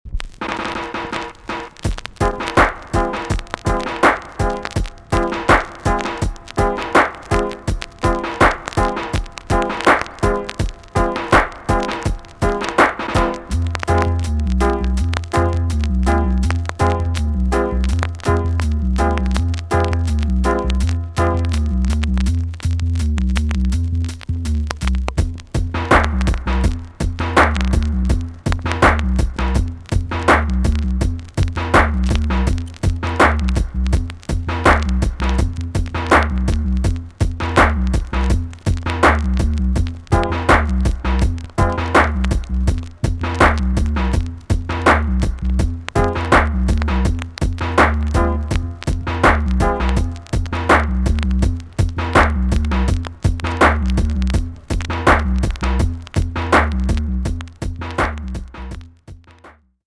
TOP >80'S 90'S DANCEHALL
VG+~VG ok 軽いチリノイズが入ります。